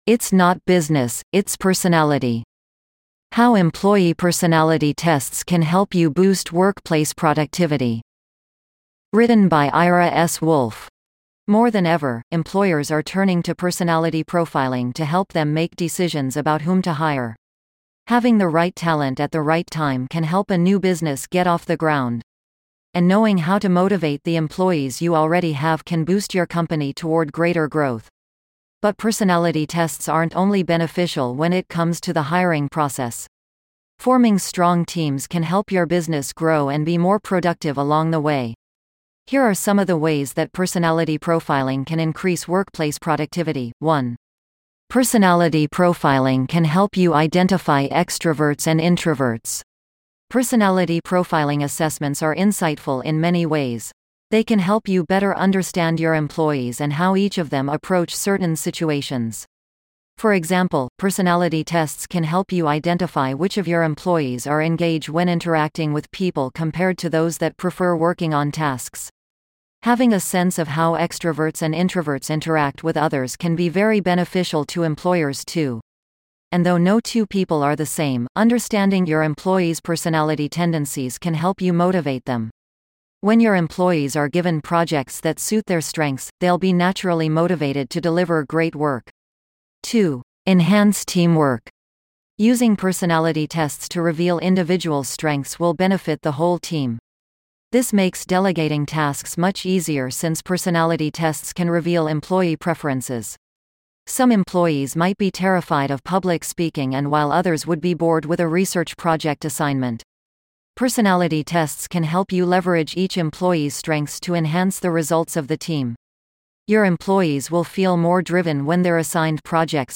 PersonalityProfiling2_en-US-Wavenet-E-1.mp3